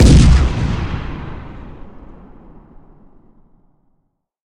cannon1.ogg